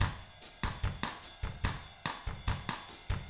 146BOSSAF2-L.wav